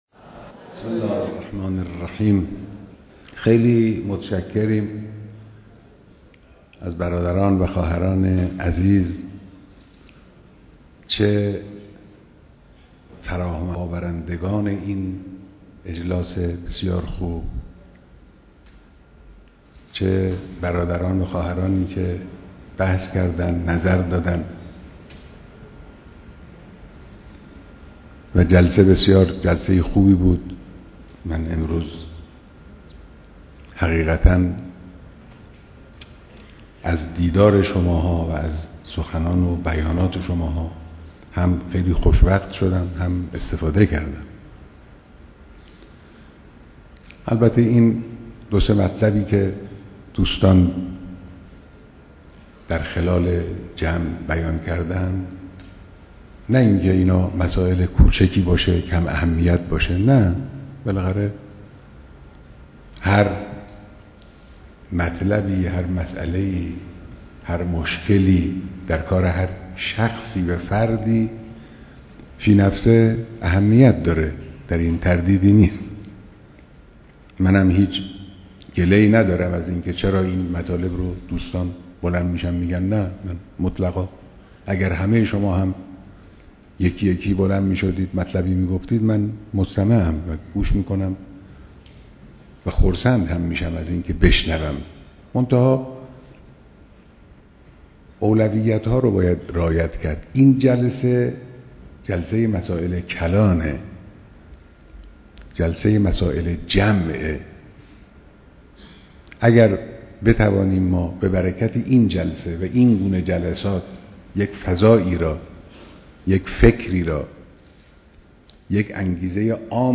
بيانات در ديدار نخبگان جوان